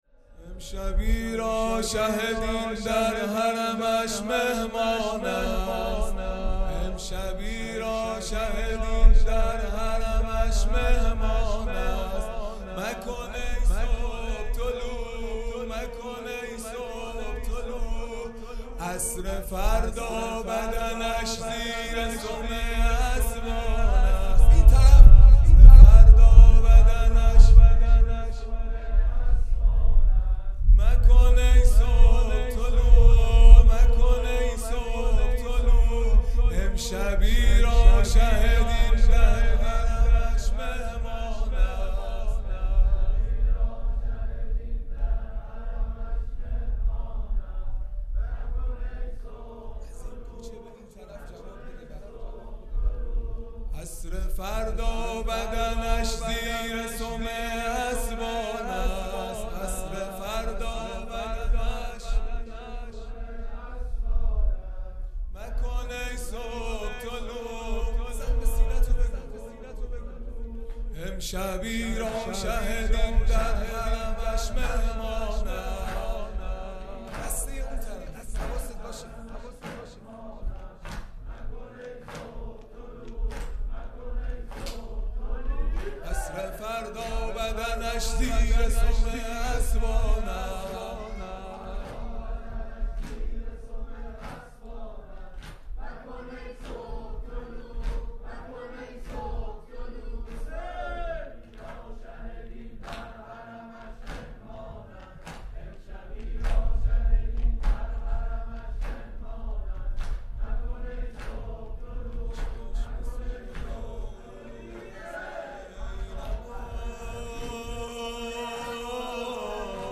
خیمه گاه - هیئت بچه های فاطمه (س) - دودمه | امشبی را شه دین در حرمش مهمانند
دهه اول محرم الحرام ۱۴۴٢ | شب عاشورا